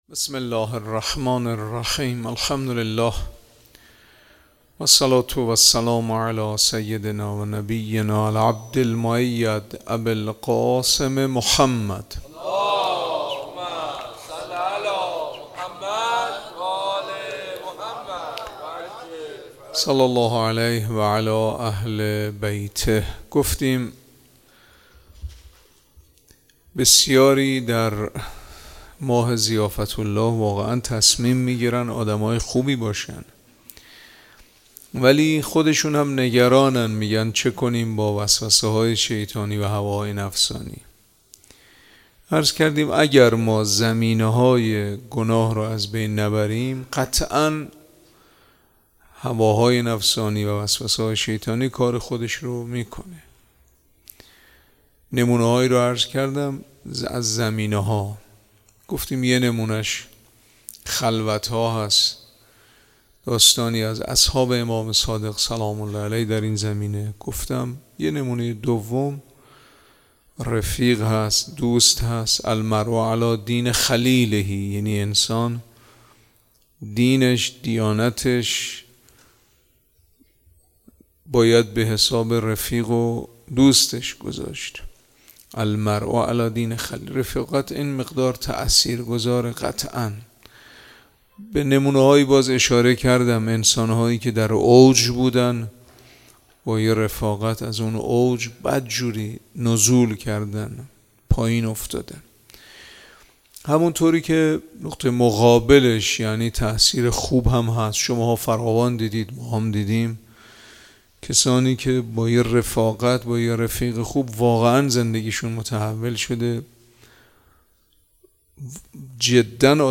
شب 4 ماه رمضان 97 - مسجد الهادی علیه السلام